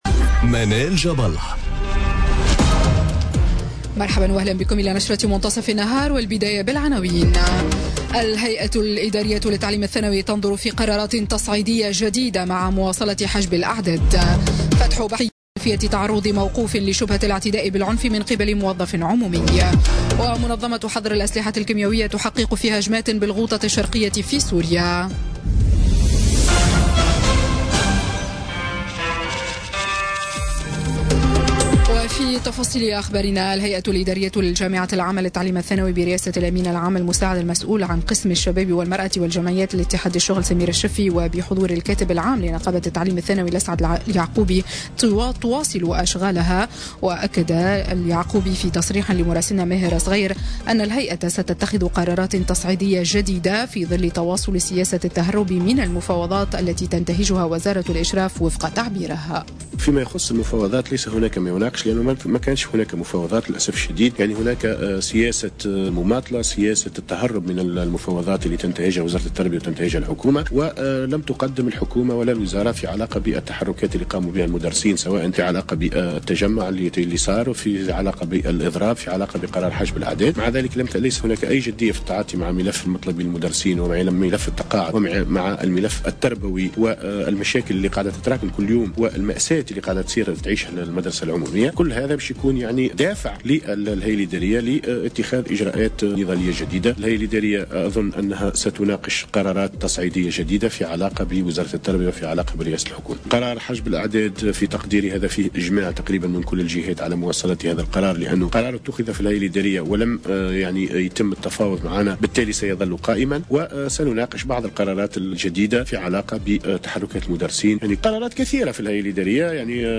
نشرة أخبار منتصف النهار ليوم الثلاثاء 27 فيفري 2018